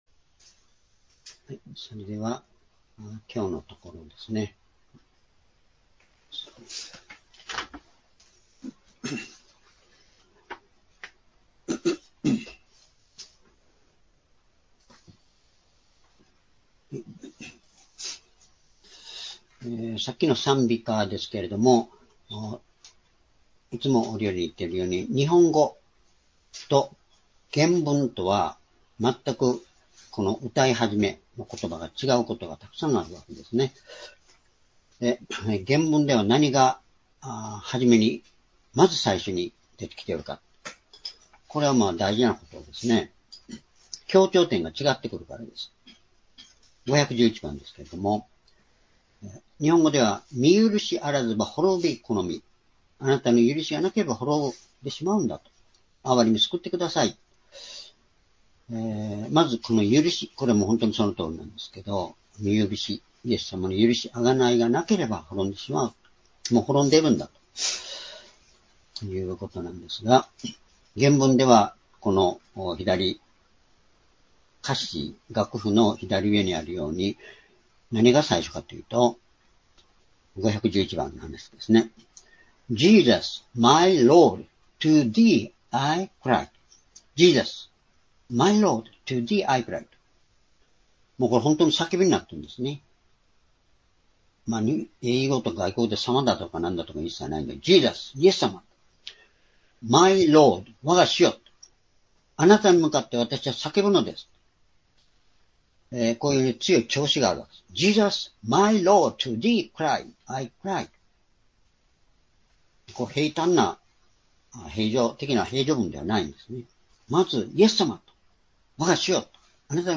「光の中を歩む」Ⅰヨハネ1章5節～7節-2025年6月29日(主日礼拝)